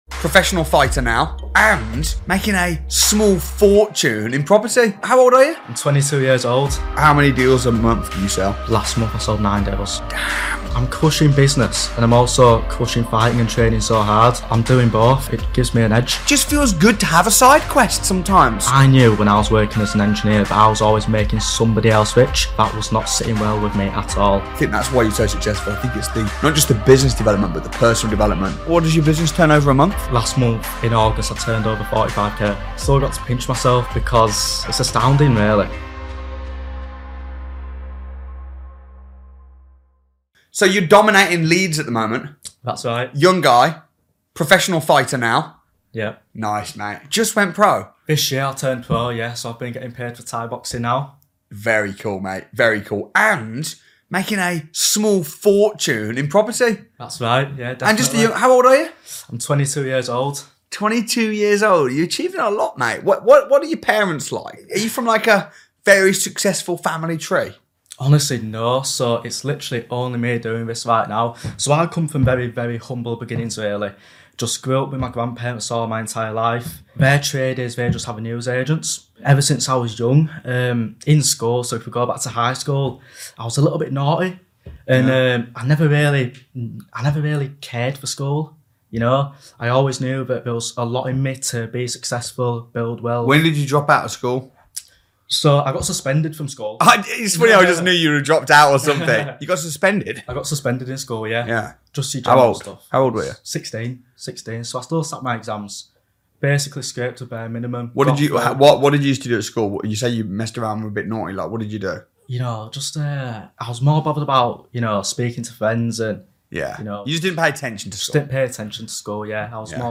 Don't miss this interview that proves it's never too late to chase your dreams and achieve greatness through property investment.